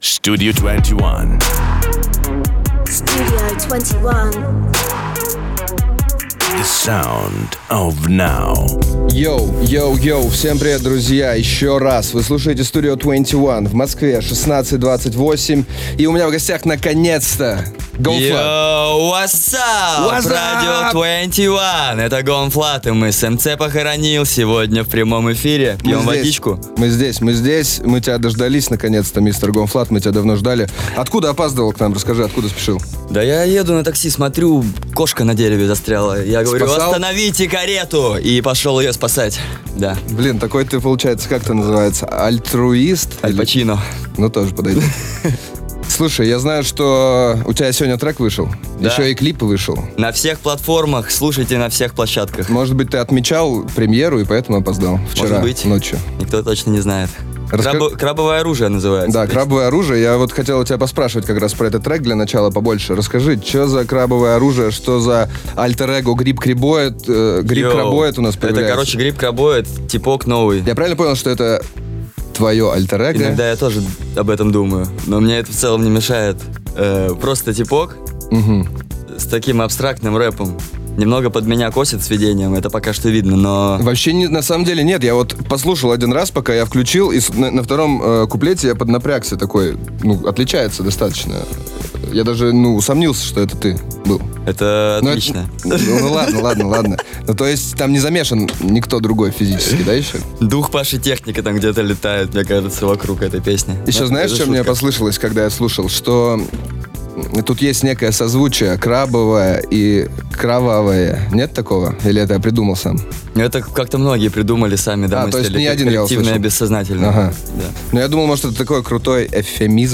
GONE.Fludd – в эфире STUDIO 21.